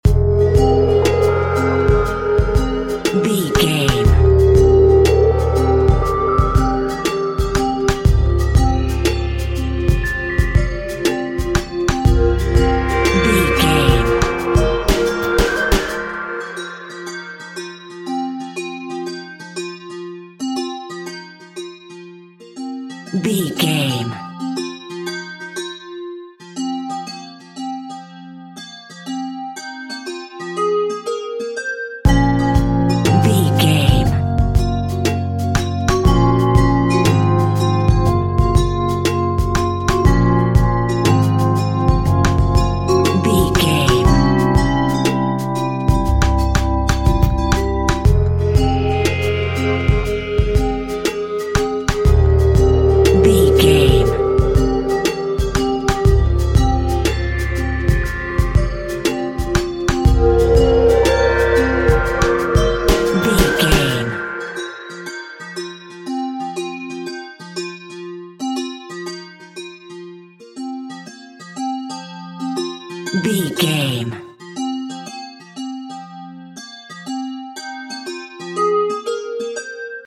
Aeolian/Minor
groovy
smooth
futuristic
uplifting
drums
synthesiser
percussion
ambient
electronic
downtempo
pads
strings
synth lead
synth bass